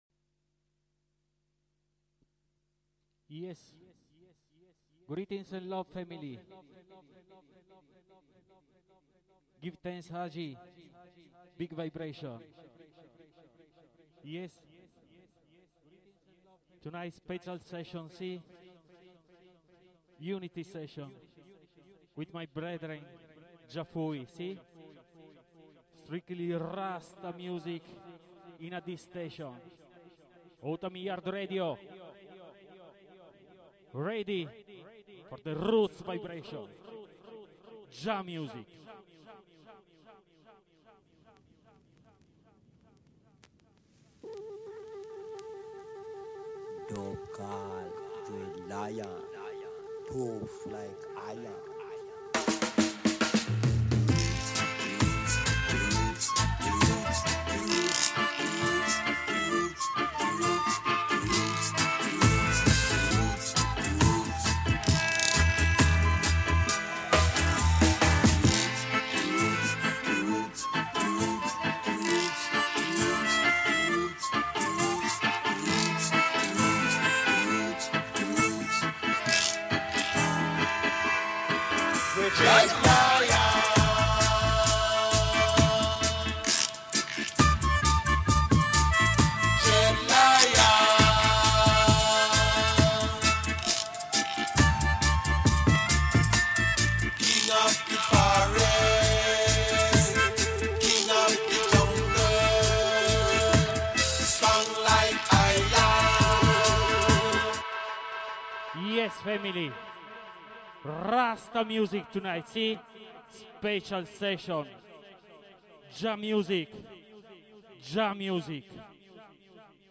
JAH MUSIC - RASTA MUSIC - VINYL SELECTION Selahhhhhhhhh !!!